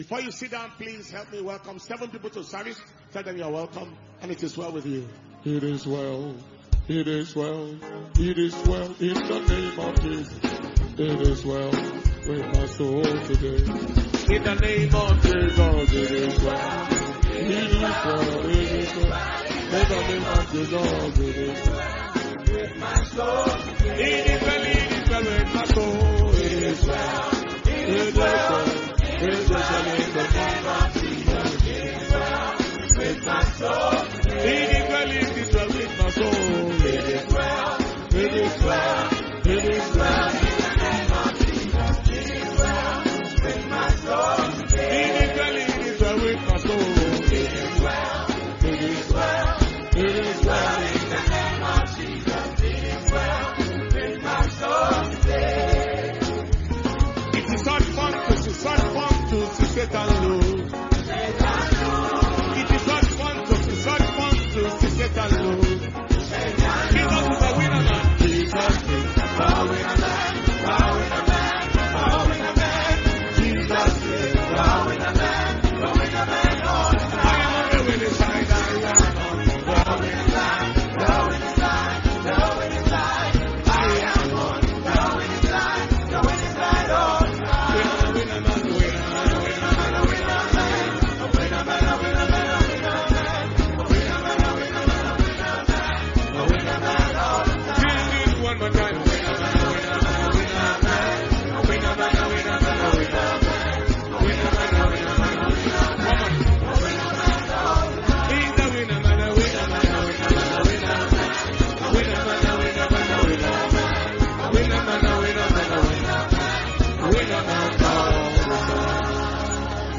Impartation Service